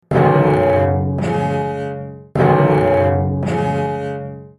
mission_failed.mp3